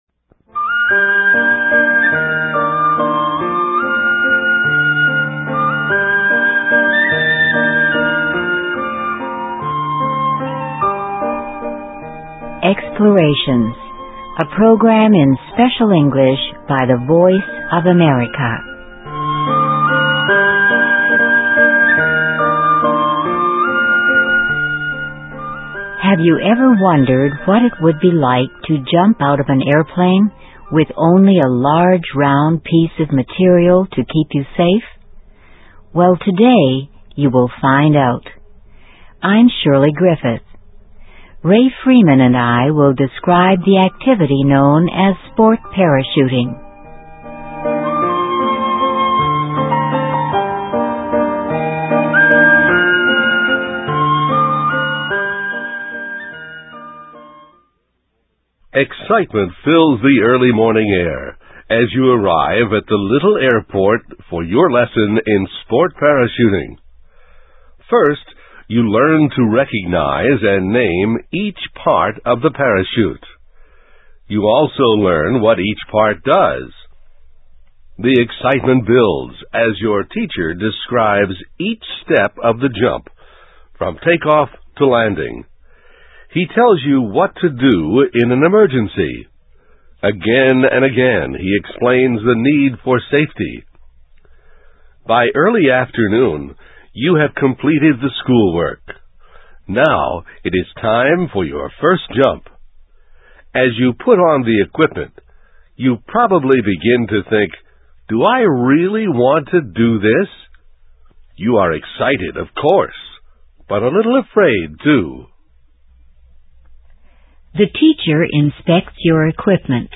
EXPLORATIONS -- a program in Special English by the Voice of America.